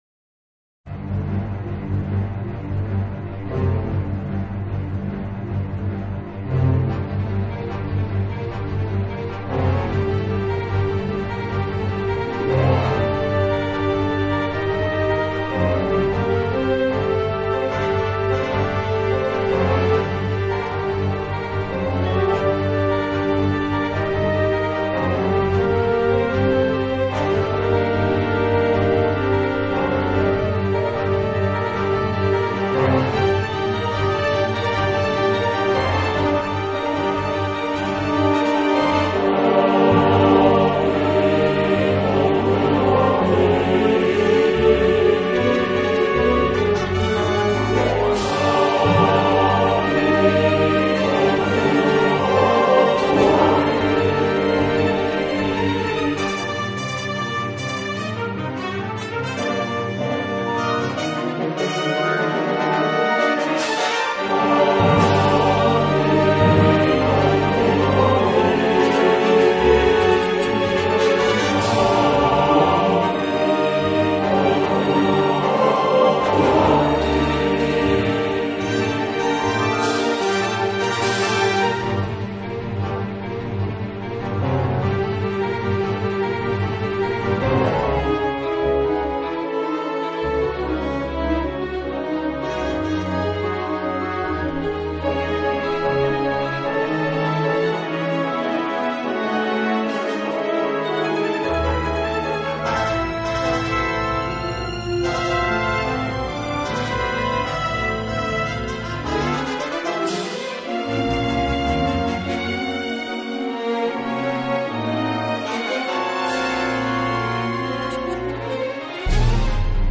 Battle of the Super Heroes, une composition pour choeurs et orchestre, pastiche de Star Wars III. Œuvre d’écoute facile, c’est vrai, mais quelle musique agréable, tonique, extravertie, quel talent, quelle virtuosité dans l’orchestration!